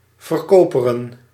Ääntäminen
Synonyymit koperen Ääntäminen Tuntematon aksentti: IPA: /vər.ˈkoː.pə.rə(n)/ Haettu sana löytyi näillä lähdekielillä: hollanti Käännöksiä ei löytynyt valitulle kohdekielelle.